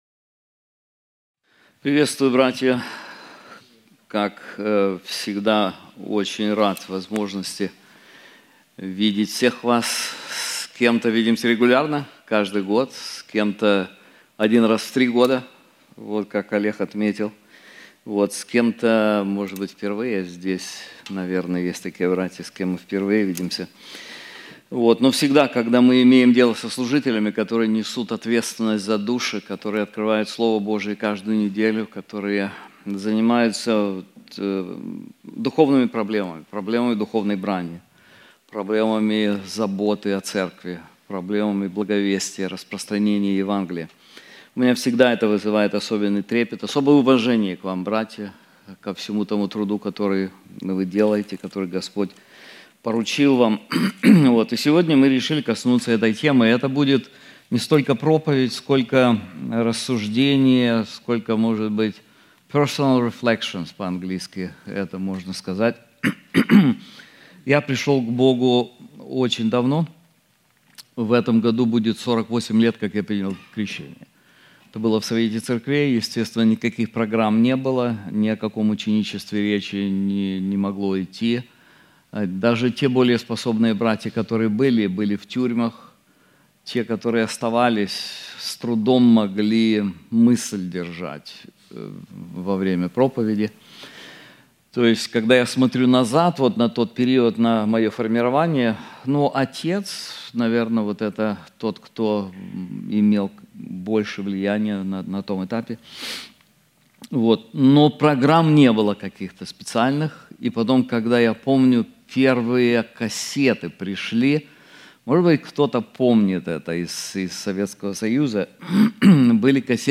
Пасторский саммит 2025